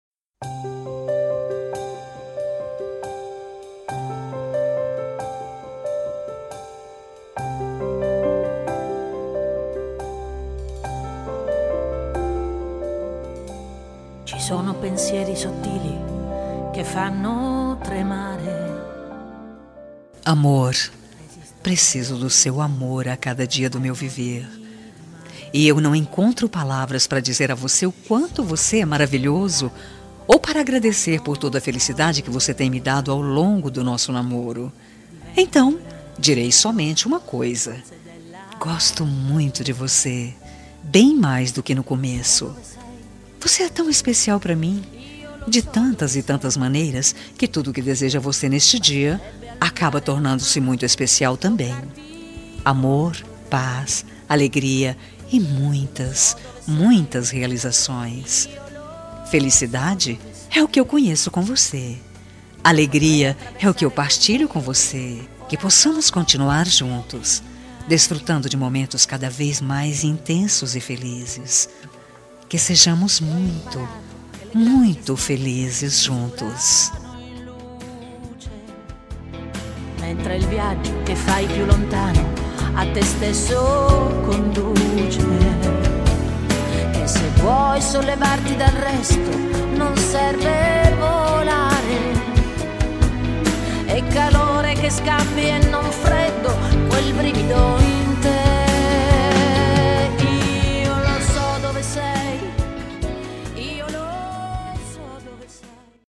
Telemensagem de Aniversário Romântico – Voz Feminina – Cód: 202117 – Suave